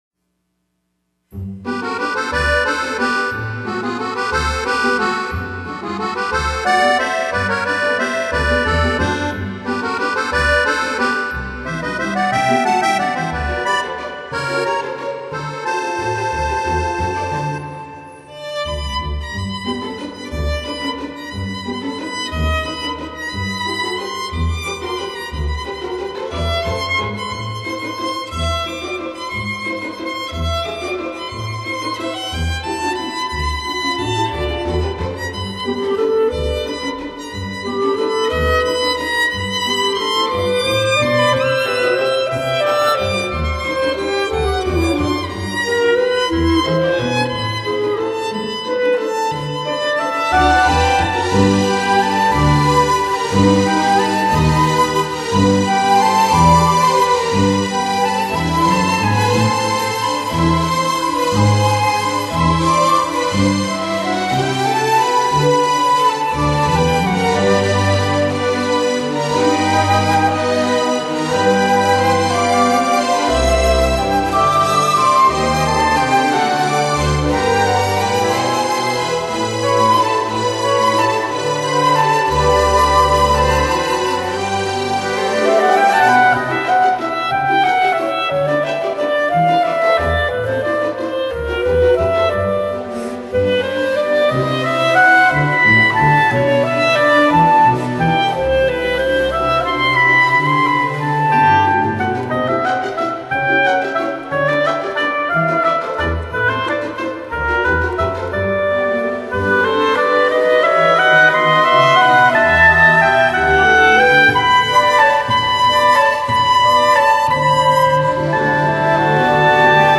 浪漫新世紀音樂
〝舞〞是祭典的重心，手舞足蹈來自於內心無盡的歡愉。